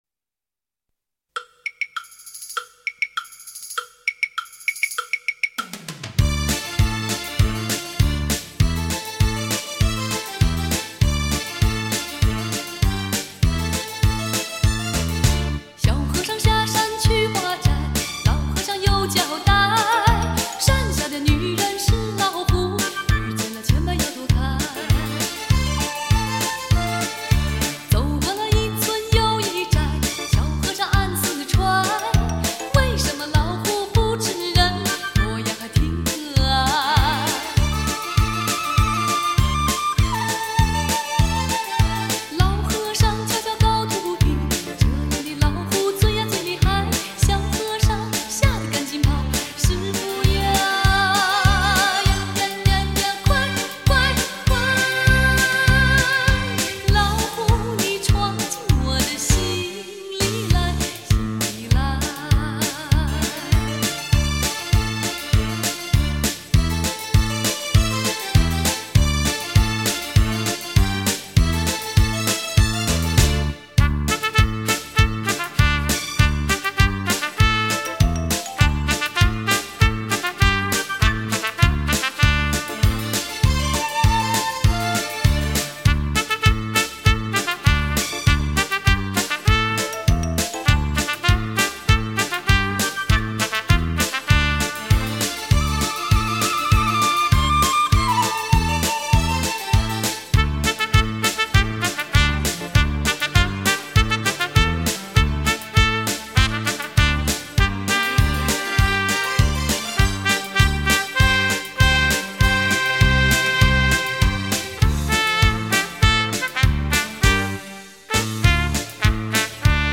[舞曲大全]